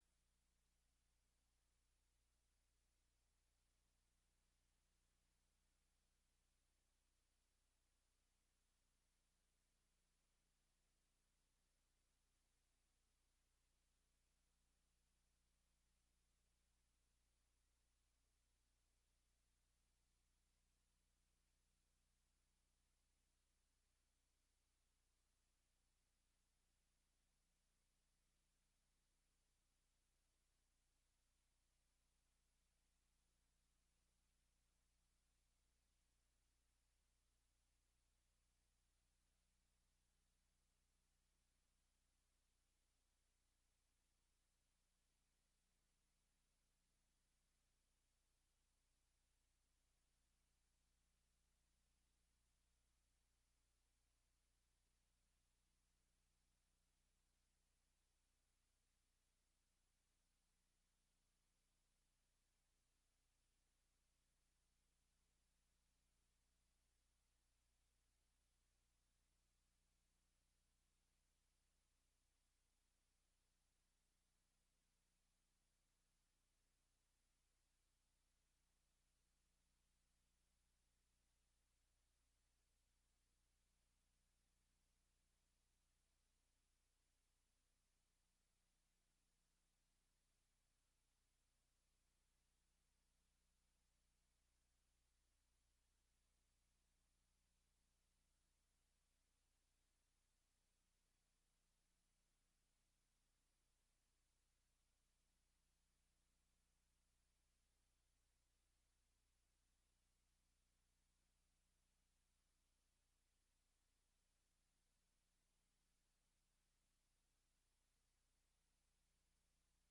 Six_Lessons_from_Samoa_Missions_Conference_Service.mp3